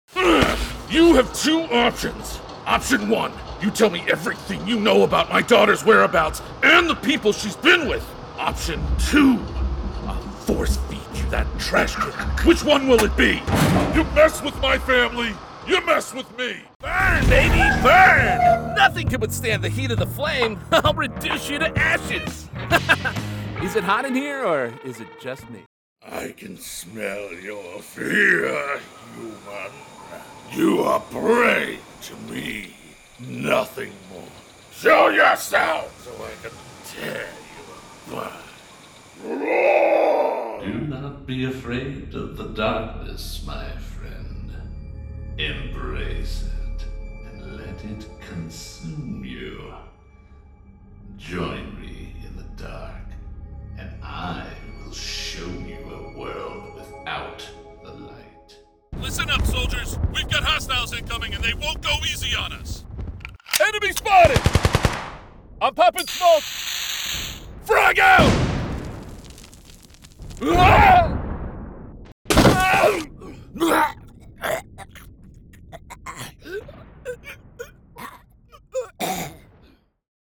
Young Adult, Adult, Mature Adult
Has Own Studio
GAMING 🎮
elderly
pirate
husky